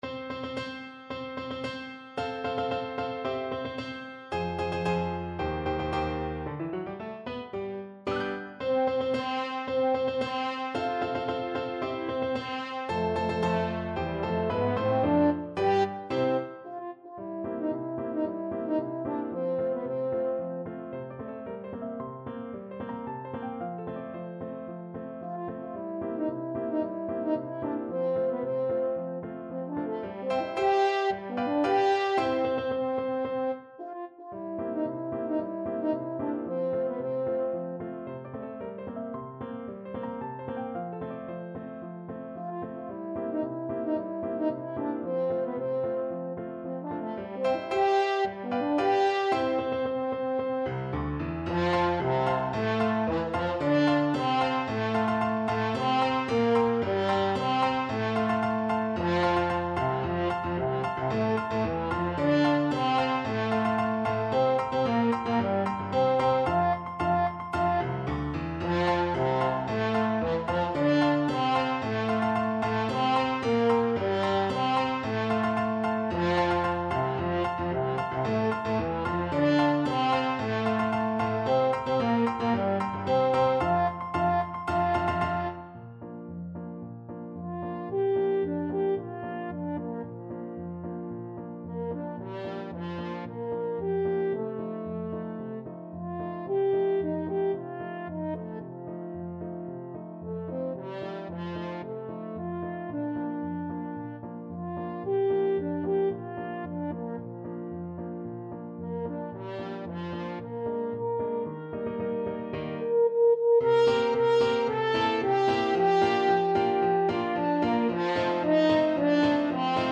2/4 (View more 2/4 Music)
~ = 112 Introduction
Classical (View more Classical French Horn Music)